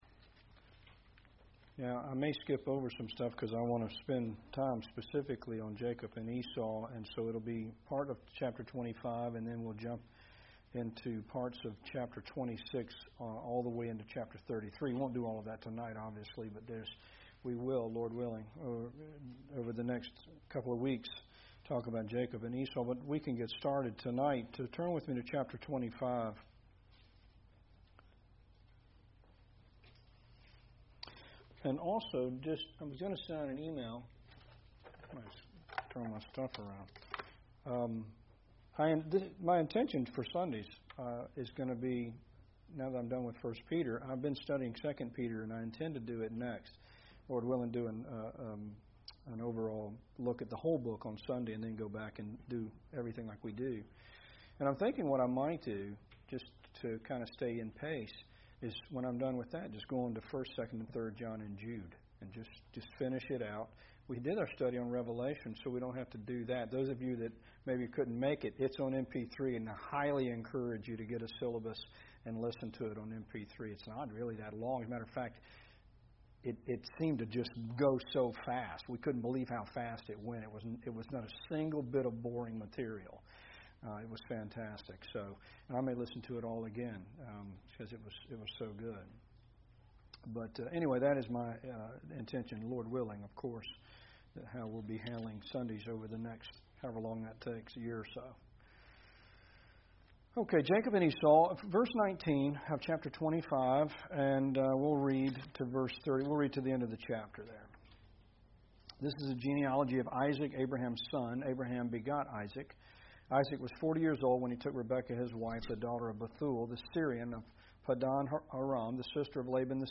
Wednesday night discussion on Esau, Jacob, and God’s prophecies concerning the two nations.